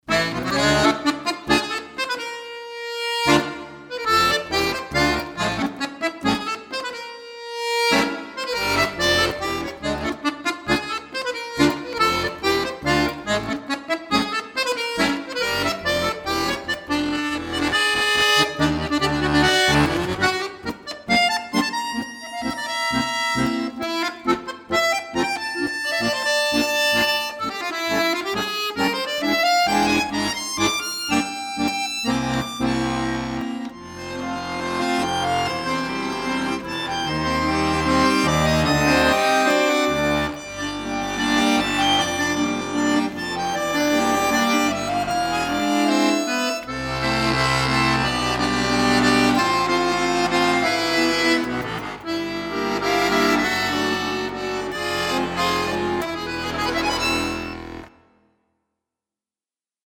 Tango Nuevo